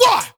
What Vox.wav